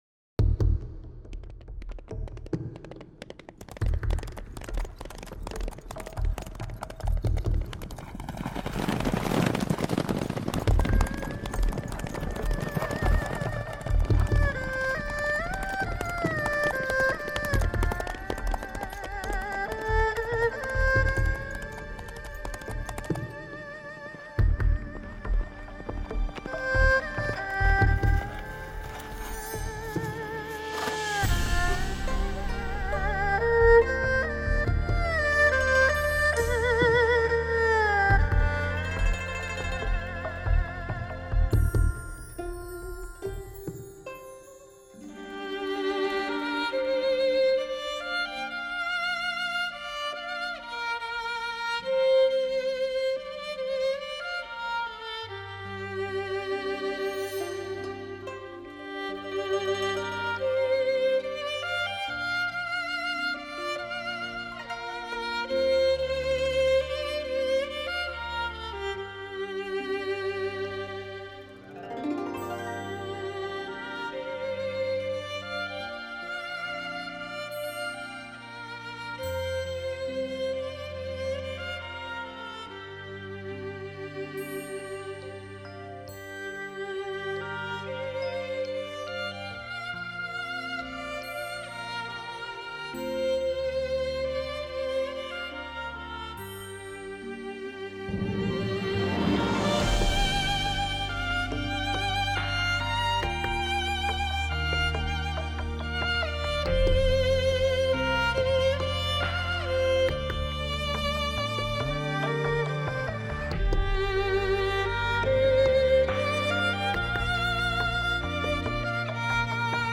一个造于18世纪的世界名琴，
无法想象的音乐享受 高级音响专用环绕声演示